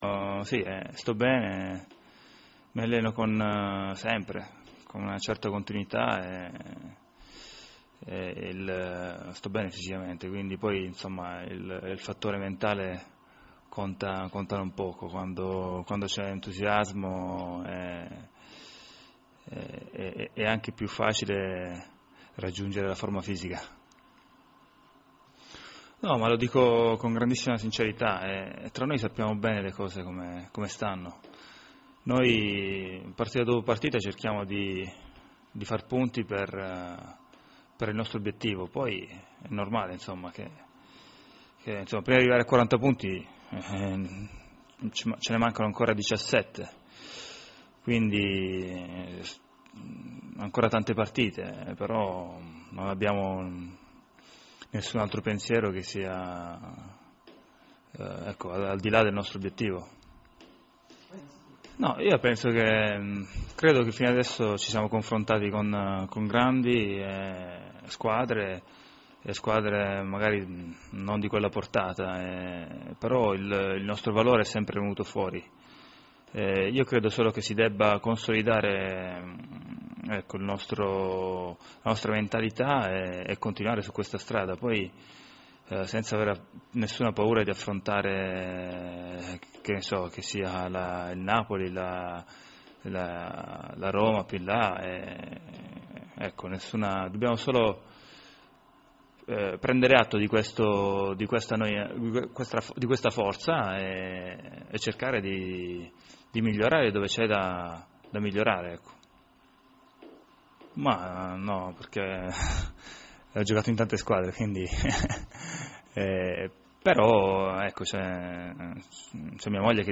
Ascolta cliccando sul tasto PLAY in alto a destra il contributo audio con l’intervista integrale a Nicola Amoruso.